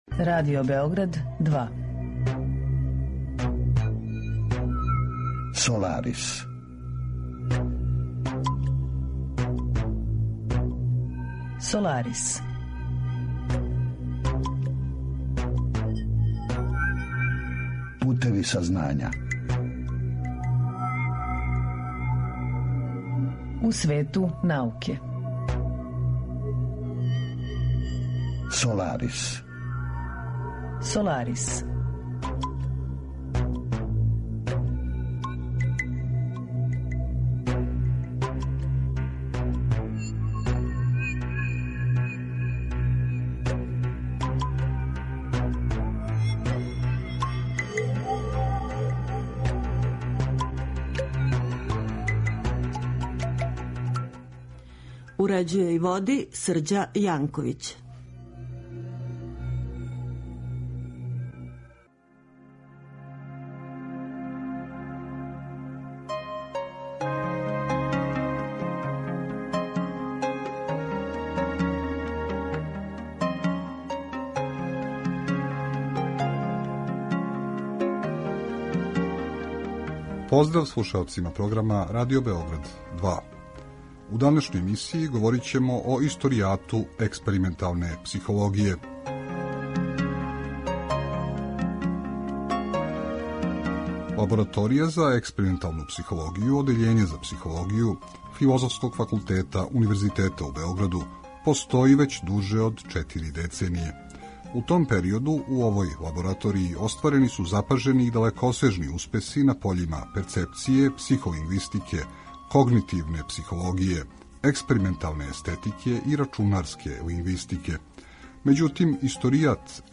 Путеви и правци којима се експериментална психологија развијала од својих зачетака до наших дана нераскидиво су уткани у ширу историју људске мисли и научног погледа на свет. Разговор је први пут емитован 22. марта 2020.